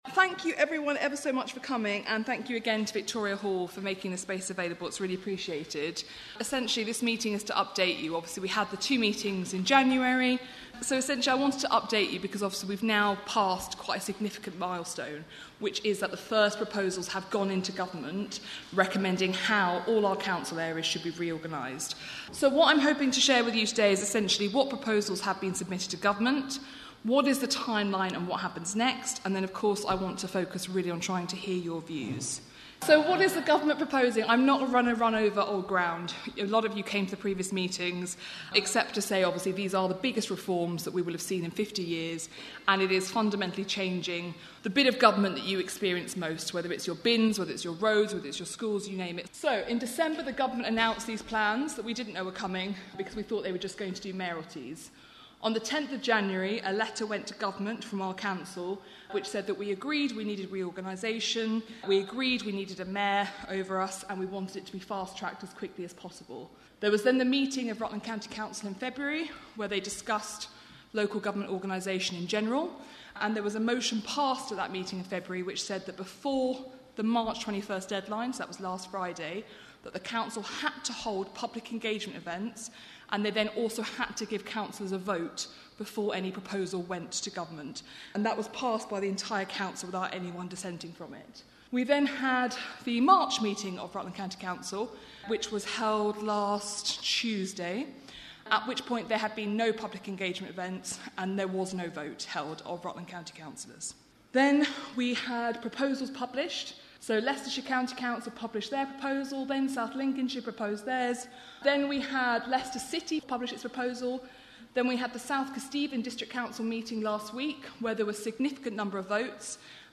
Recorded at Victoria Hall, Oakham on Monday 24th March
The meeting was Chaired and hosted by Alicia Kearns MP, the leader of South Kesteven District Council also attended. The meeting focused on options that have been presented that involve Rutland.